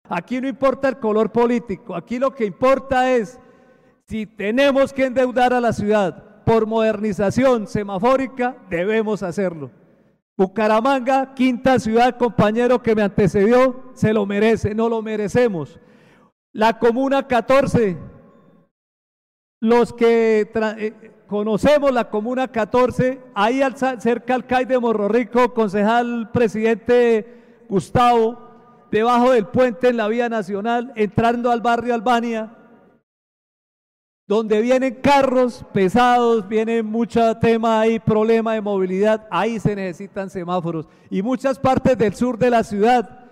Christian Reyes, concejal de Bucaramanga dice que la ciudad tiene los peores semáforos del mundo